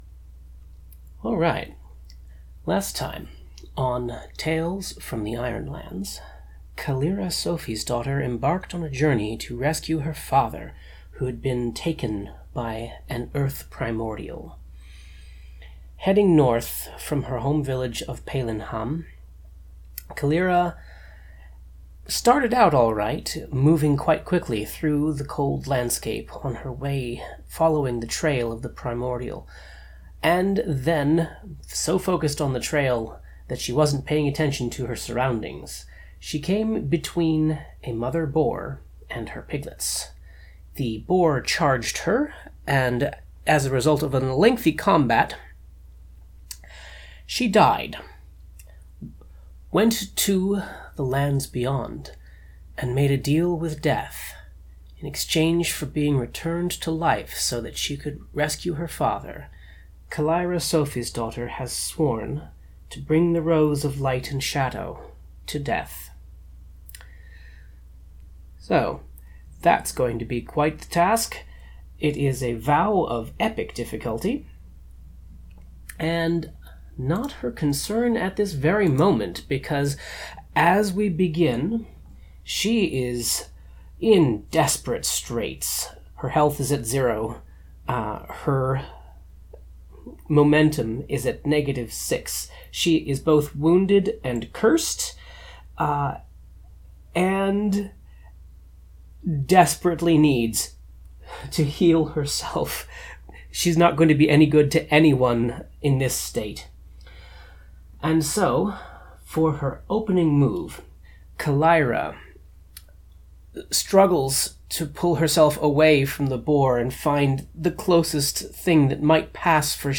Note: This has been edited to remove uninteresting bits — in all, I cut about 22 minutes of silence, paper shuffling, umms, ahhs, and muttering of rules passages.